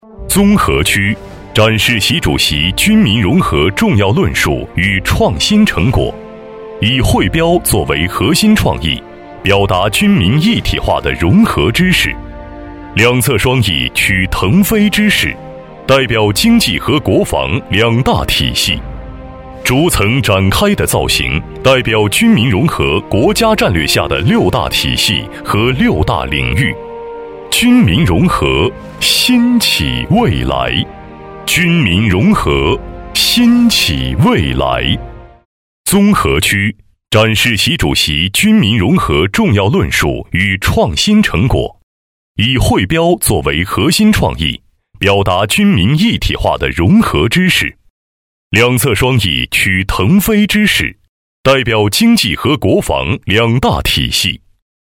军政男206号（大气浑厚）
大气浑厚男音，厚重。